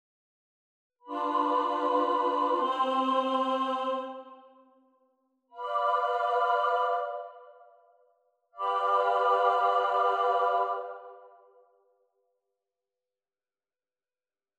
VII6 en I